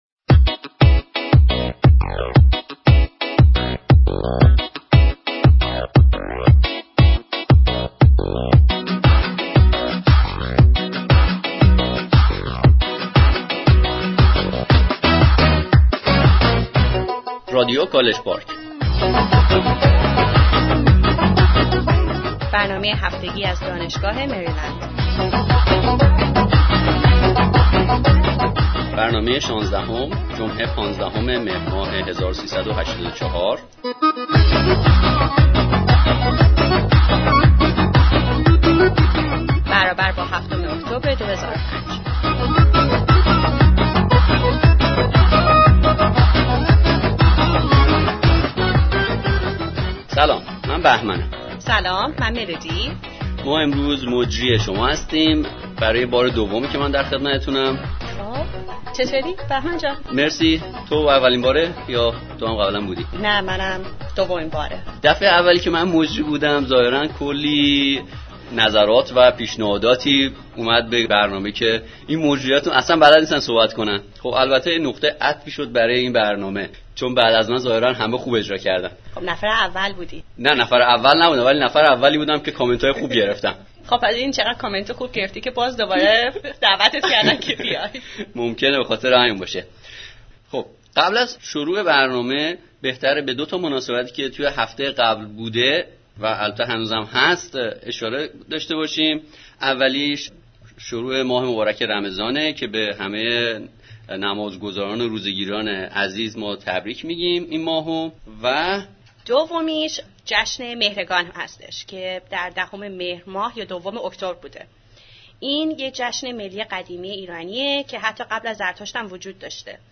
Interview with Tennis Champions of University of Maryland